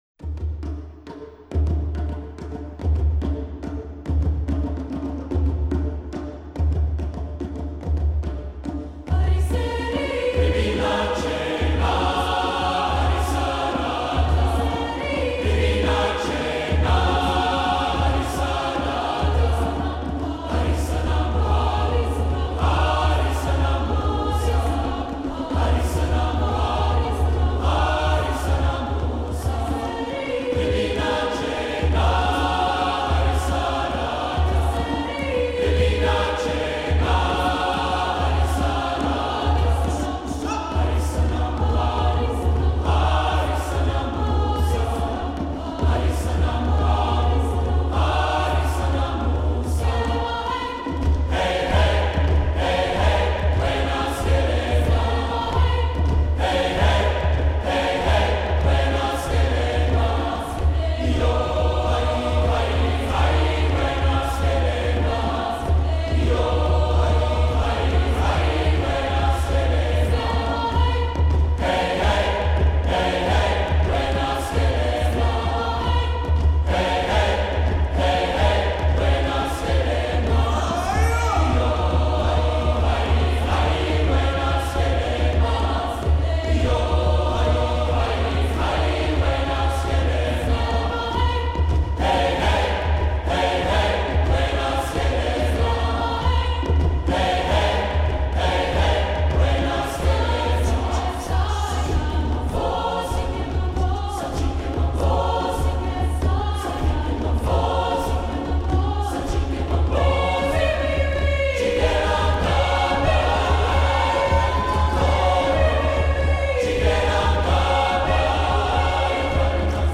Voicing: SSATB